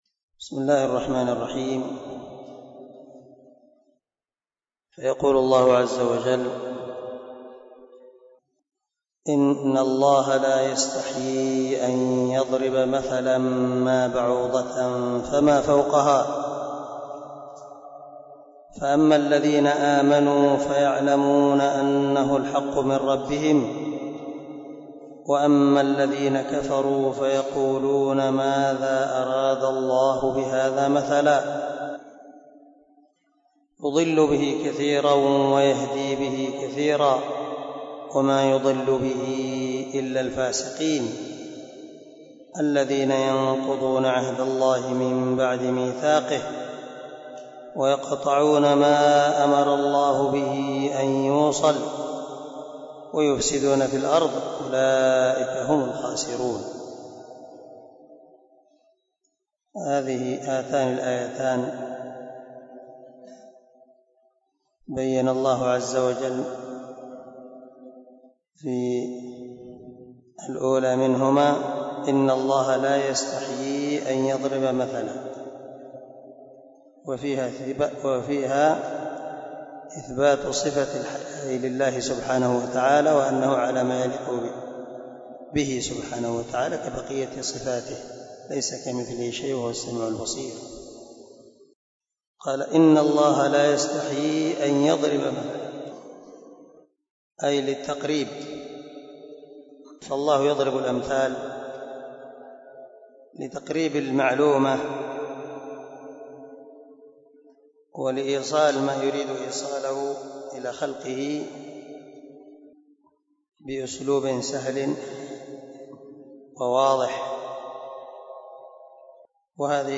021الدرس 11 تفسير آية ( 26 - 27 ) من سورة البقرة من تفسير القران الكريم مع قراءة لتفسير السعدي